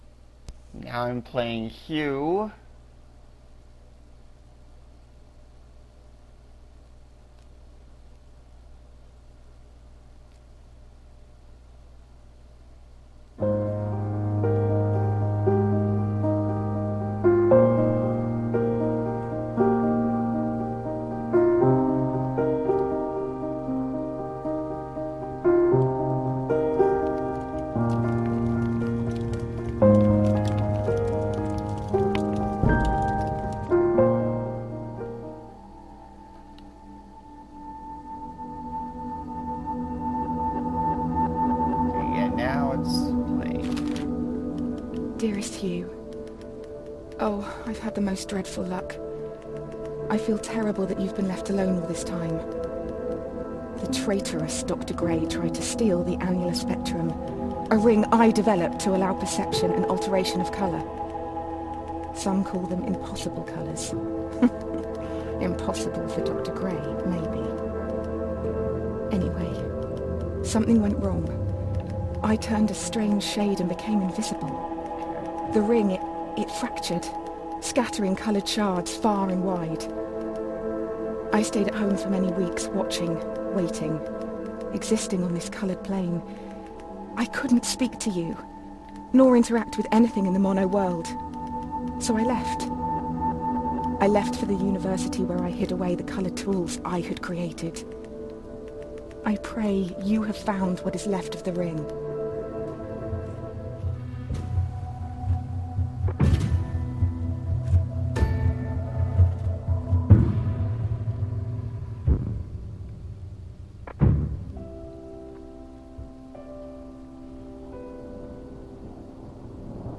I play Hue with commentary